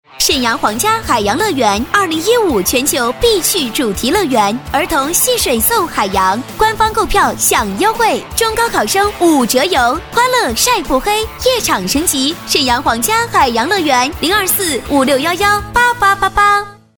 女国171_广告_促销_海洋乐园_活力.mp3